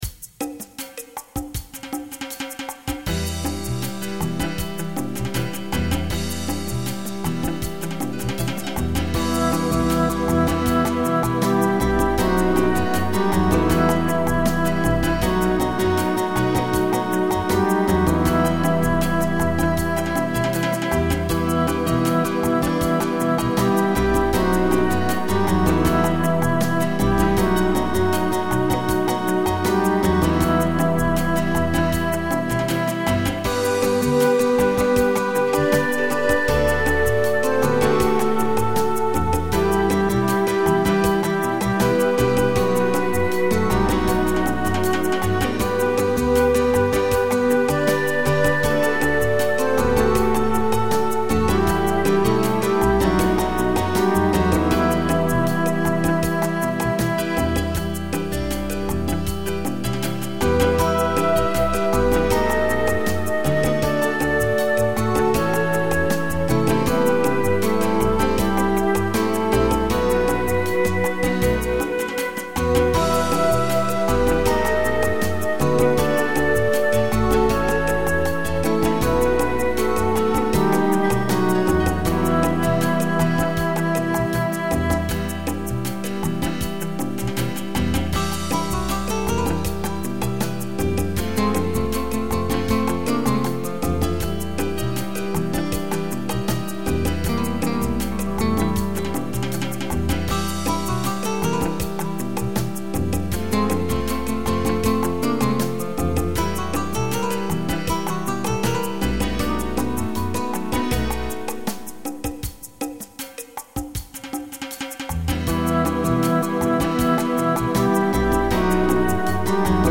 You may have a little trouble finding musicians to recreate this sound outside of Brazil, but don't let that stop you from trying.
Recording from MIDI mp3 Play the midi file View the Music Score Publish date: 1998-03-08 Song displays: 33765 Song votes: 60 Share this song with a friend